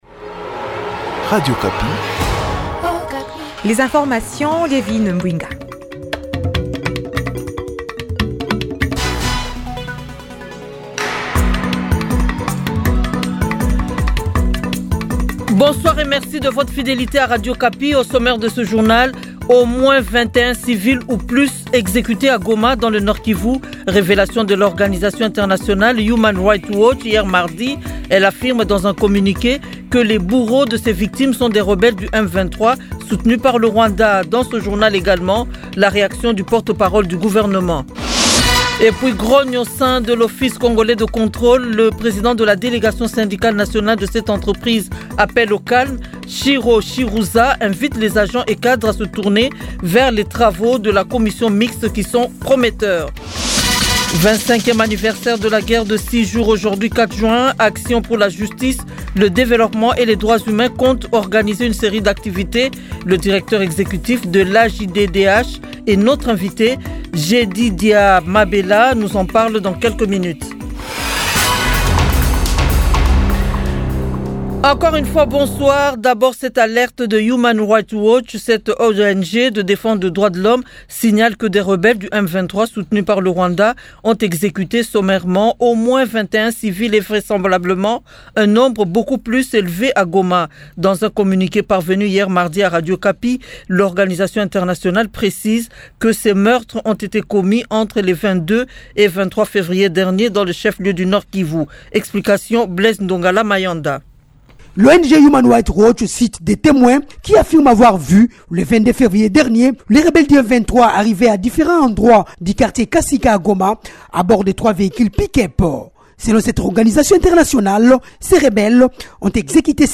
Journal soir mercredi 4 juin 2025